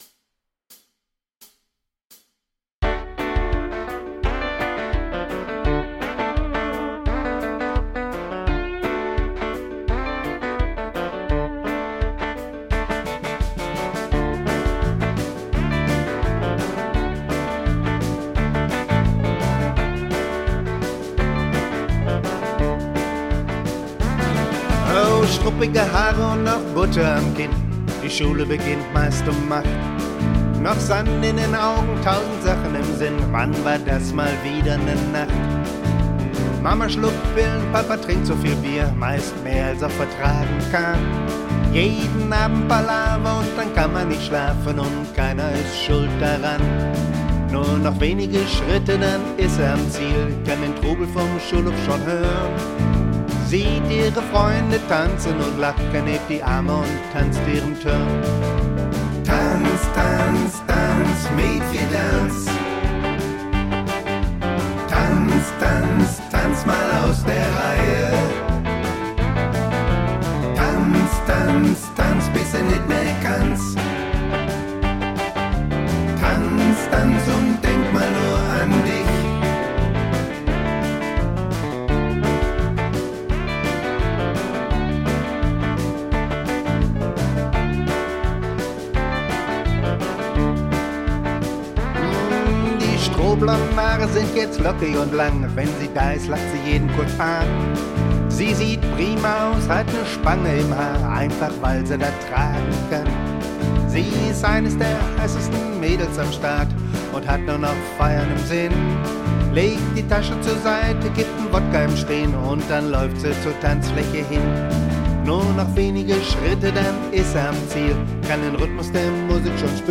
Ich habe versucht den Sound und Groove von J.J. Cale einzufangen.